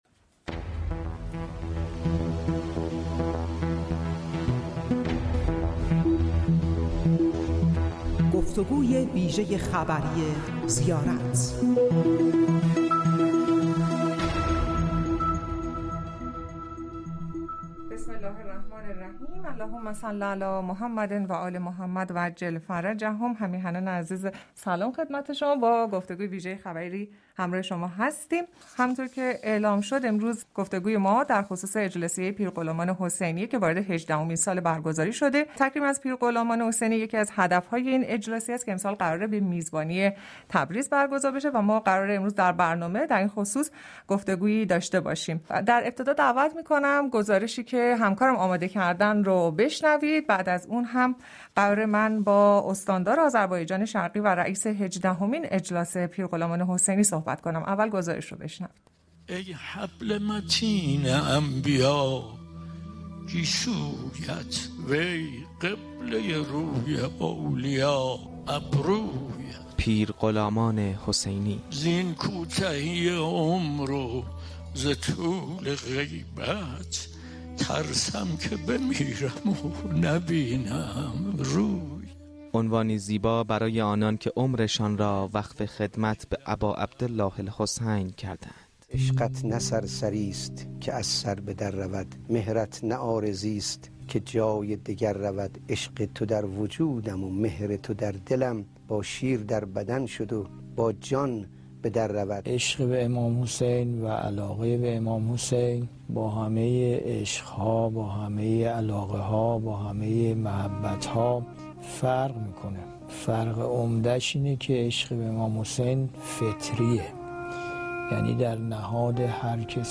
گفتگوی ویژه خبری